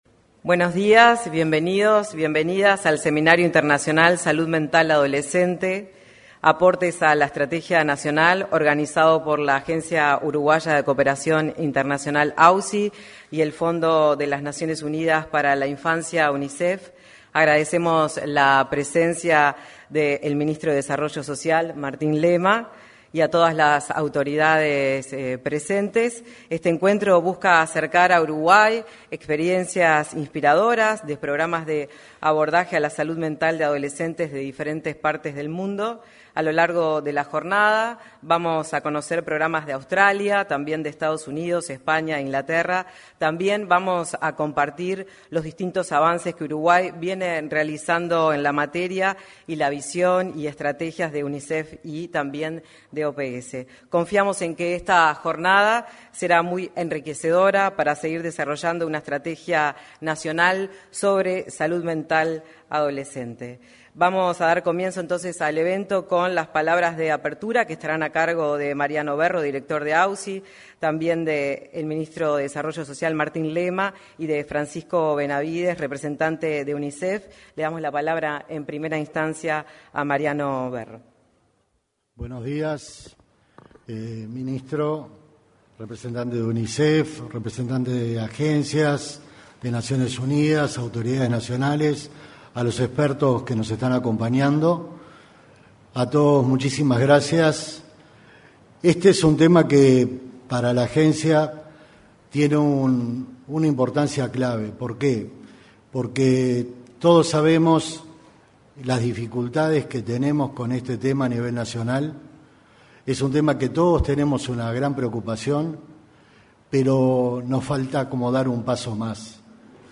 En la apertura del evento participaron el director ejecutivo de la Agencia Uruguaya de Cooperación Internacional (AUCI), Mariano Berro; el